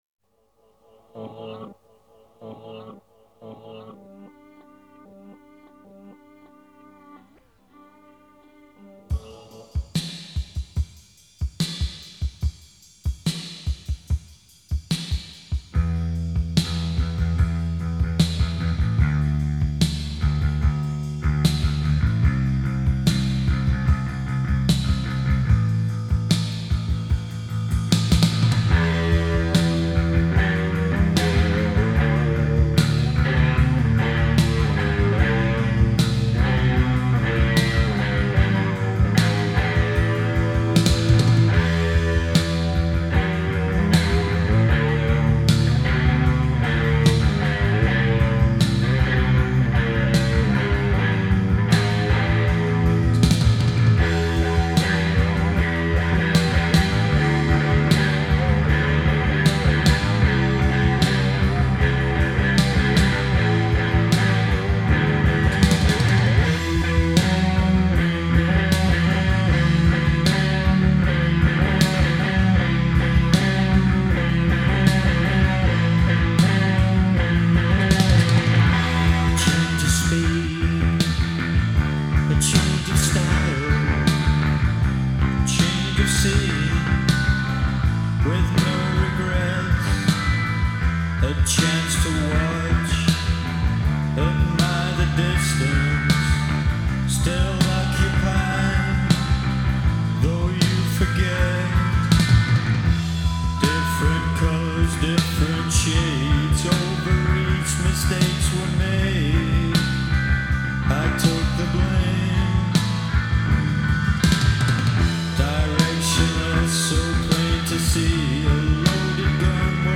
Gothic post punk goth
پست پانک راک